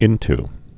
(ĭnt)